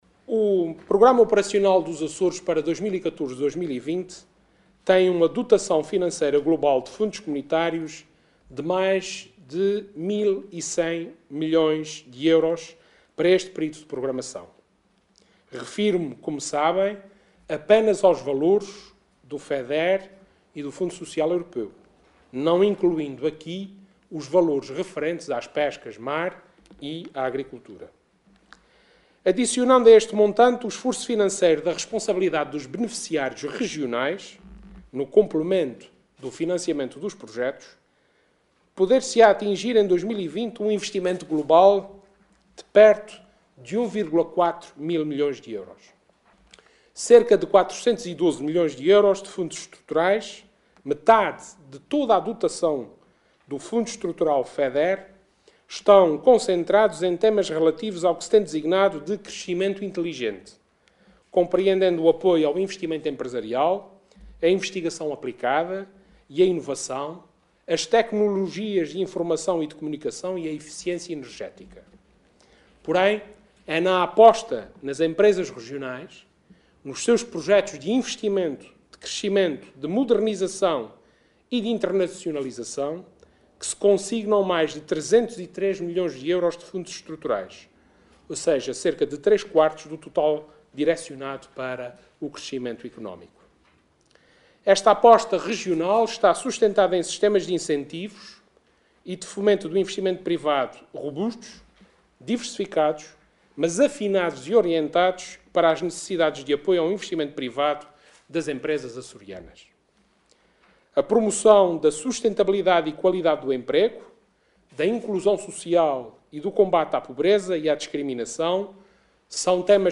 “O desafio com que os Açores estão confrontados neste domínio, nos investimentos, quer públicos, quer privados, não é apenas o de alocar recursos. É, sobretudo, o de obter resultados concretos, visíveis e mensuráveis”, afirmou Vasco Cordeiro, numa conferência de imprensa em Ponta Delgada.